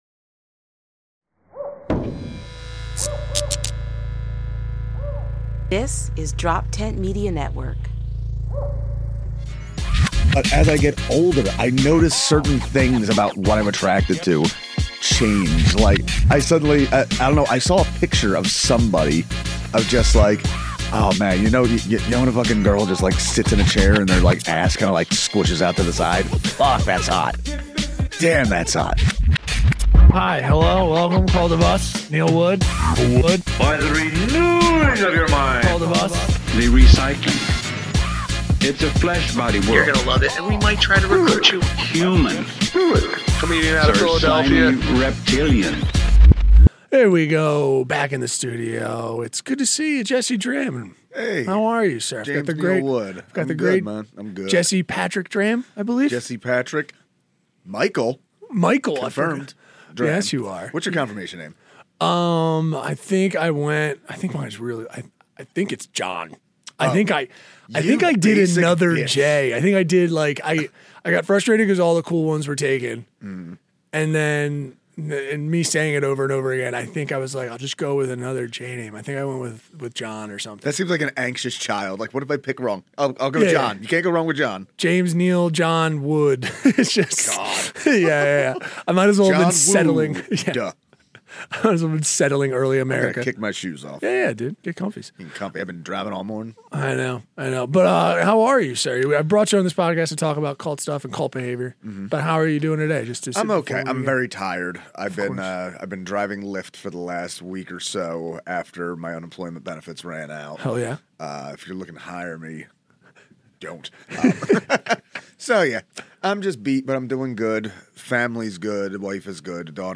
Genres: Comedy , Comedy Interviews , Stand-Up